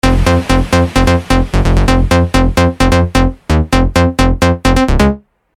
BASS/LEAD 2007 года
Задача найти/накрутить конкретно этот звук, с характерным писком противофаз в осциляторах.